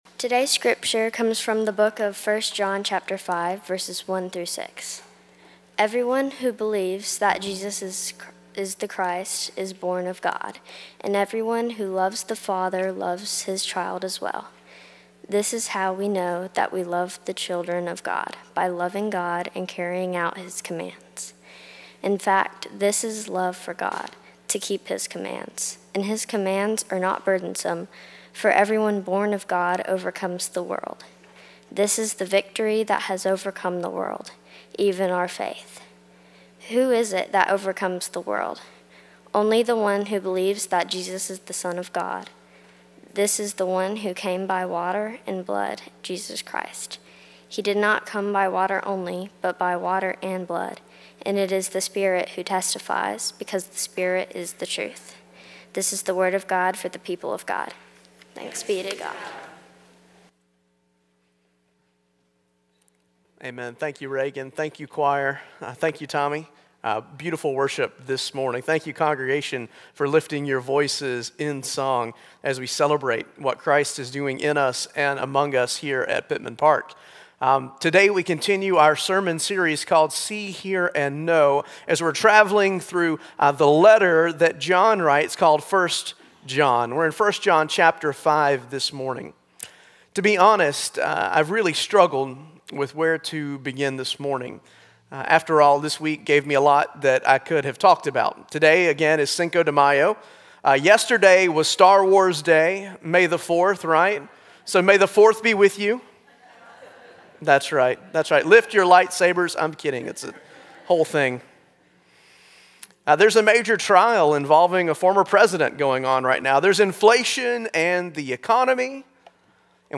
Passage: 1 John 5: 1-6 Service Type: Traditional Service